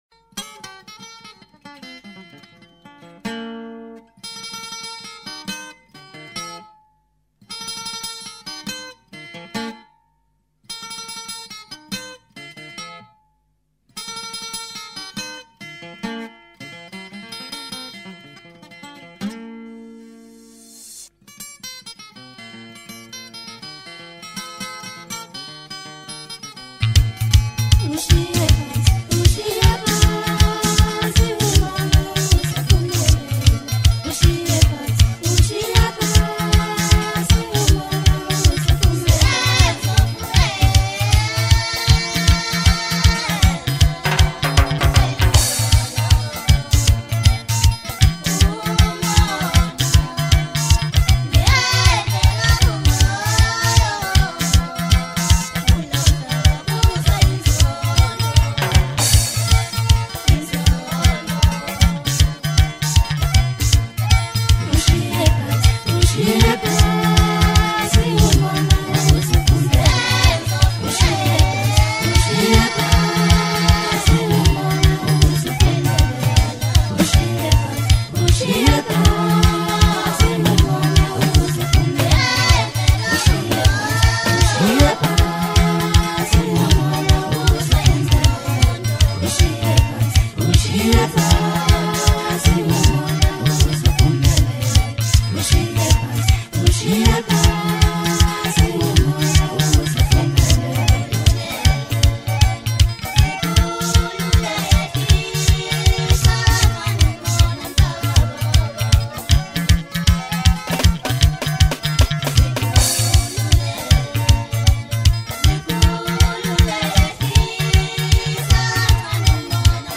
Home » South African Music